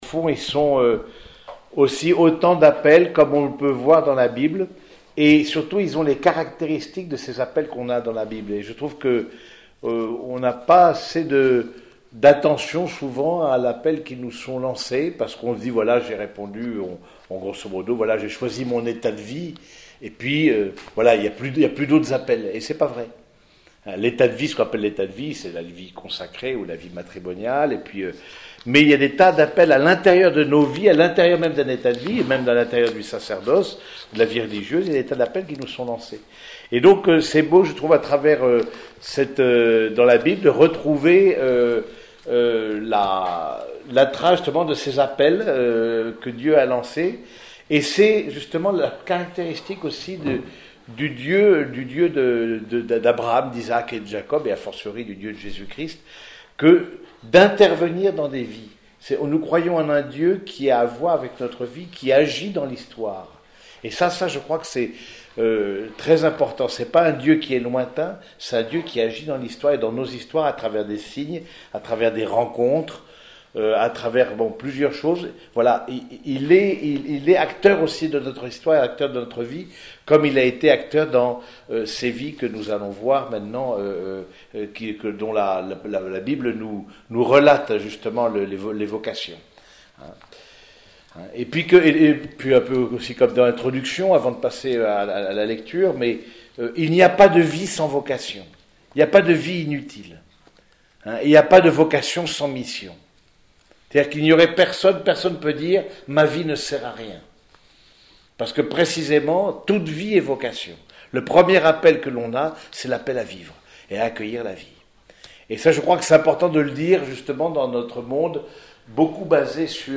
Écouter les enseignements :